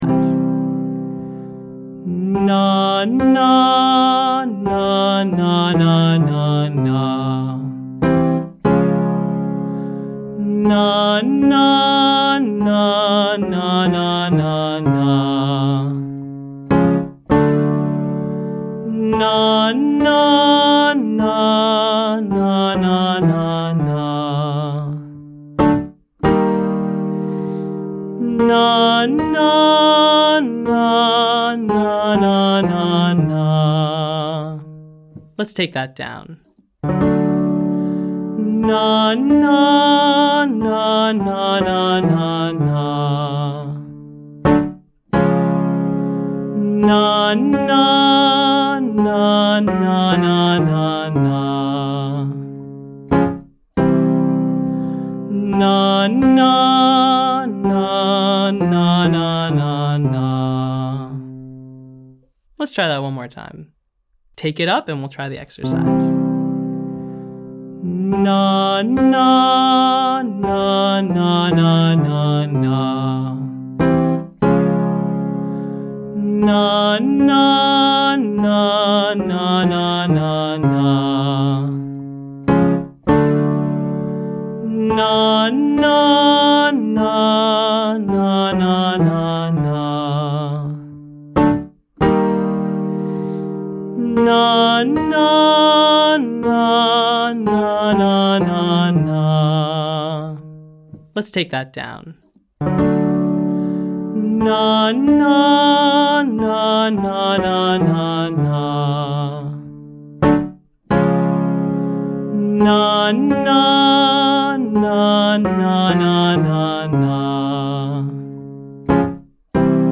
Other Patterns - Online Singing Lesson
From: Daily Intonation Practice For Low Voices : Major Scale & Interval Focus
This first pattern begins on step 5. So it’s a skip, then steps coming down.